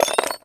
metal_small_movement_09.wav